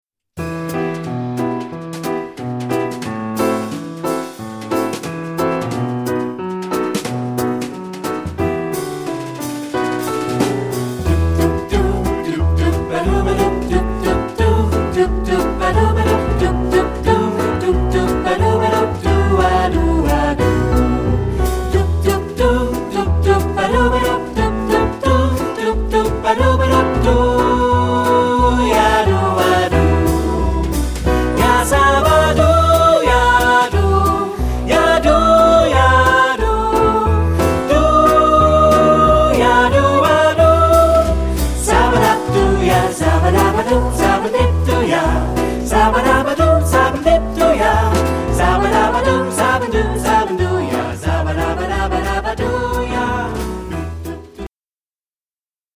Kanon ; Choraljazz
jazzy ; fröhlich ; rhythmisch
Ad libitum (4 Ad libitum Stimmen )
Tonart(en): e-moll